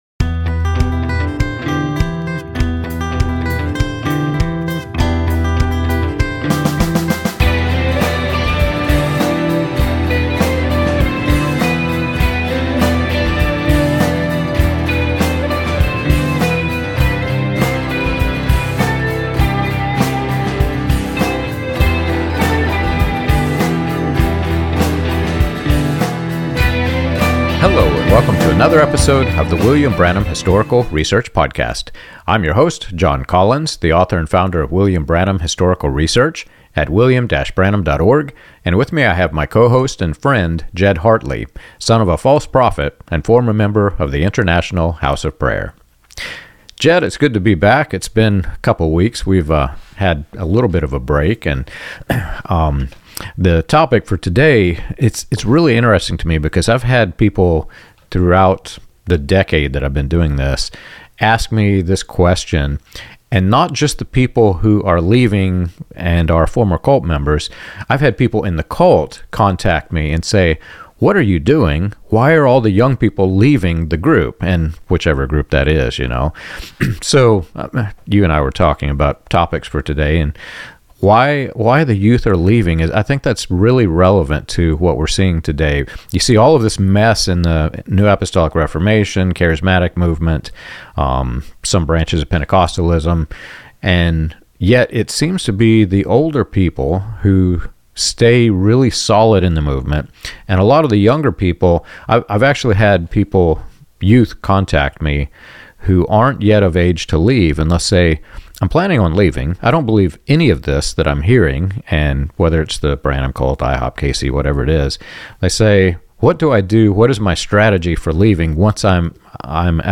This conversation is especially relevant for anyone following current debates around IHOPKC, Bethel, prophetic ministry, Pentecostal excess, and the future of charismatic Christianity.